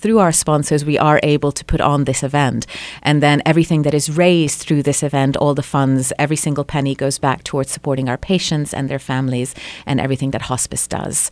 a recent guest of the Talk of the Town on KTLO-FM